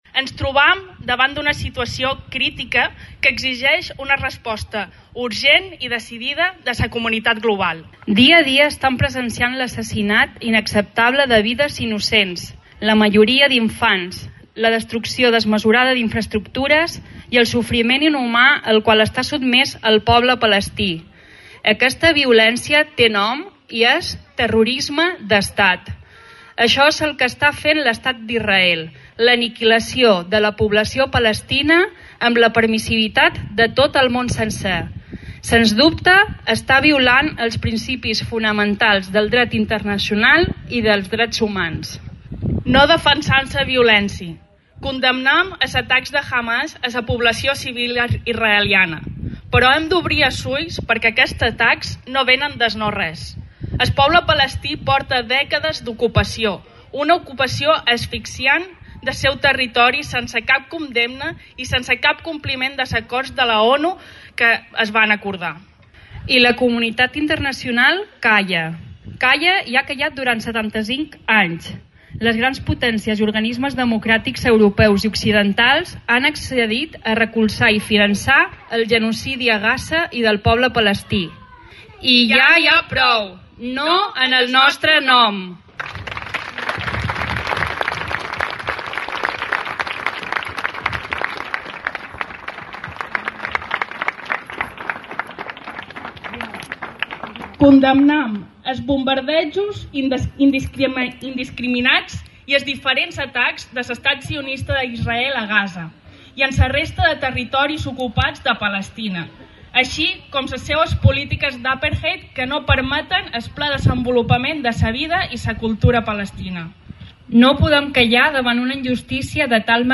Lectura del manifest a la plaça. 7bts
Organitzat per la Plataforma Formentera Palestina, ha comptat amb la lectura d’un manifest que podeu escoltar clicant al següent enllaç…